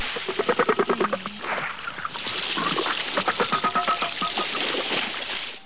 Would like to hear what sounds a Gray whale makes ?
greywale.wav